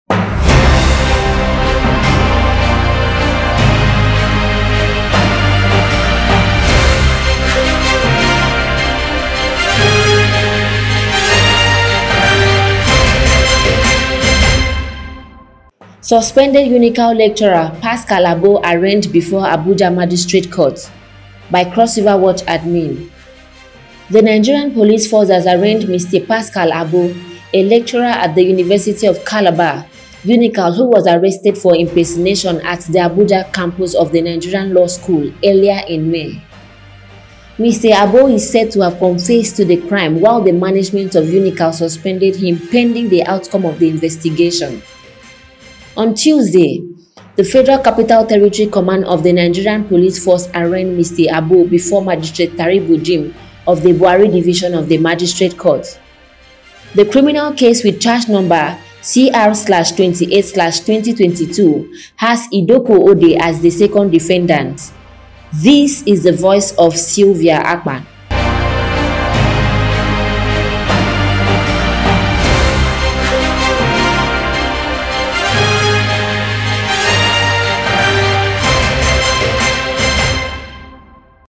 Breaking News CrossRiverWatch TV Education Podcast